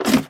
MinecraftConsoles / Minecraft.Client / Windows64Media / Sound / Minecraft / step / ladder1.ogg
ladder1.ogg